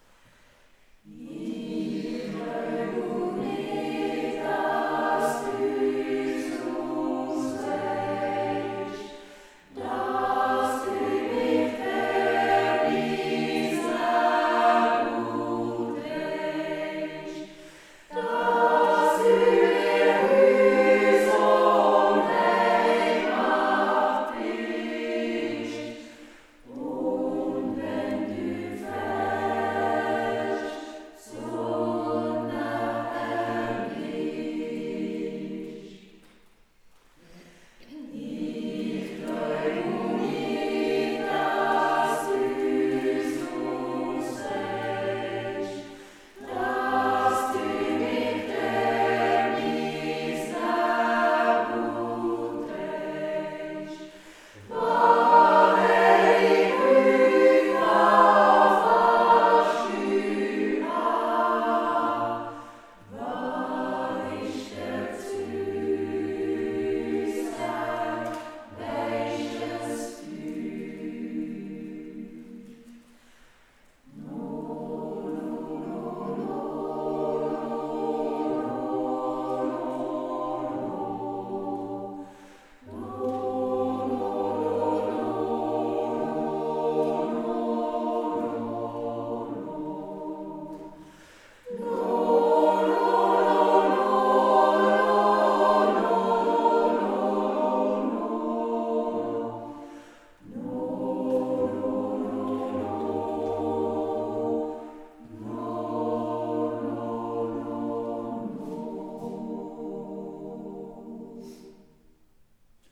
Seit 2018 ist die SingWerkstatt ein gemischter Chor, wobei die Herren erst im Januar 2024 Geschmack an uns gefunden haben.